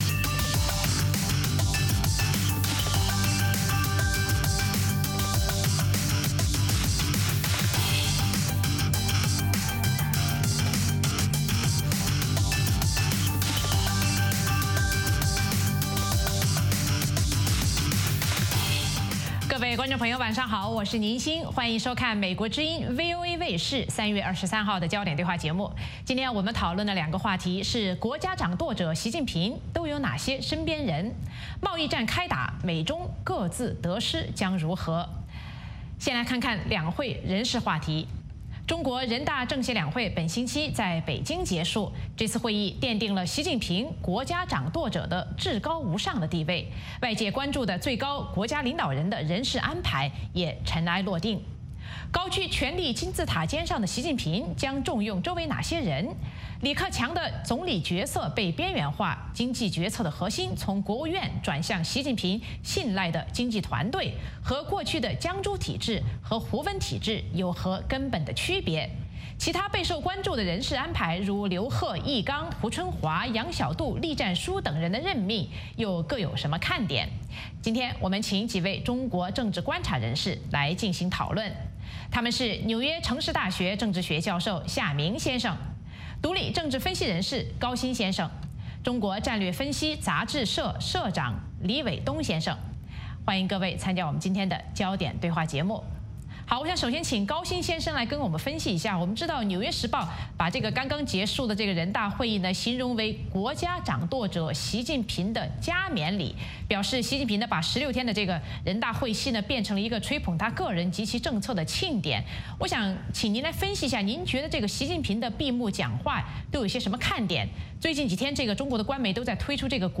美国之音中文广播于北京时间早上6－7点重播“焦点对话”节目。《焦点对话》节目追踪国际大事、聚焦时事热点。邀请多位嘉宾对新闻事件进行分析、解读和评论。